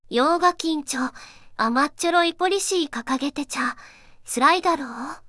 voicevox-voice-corpus
voicevox-voice-corpus / ROHAN-corpus /四国めたん_セクシー /ROHAN4600_0011.wav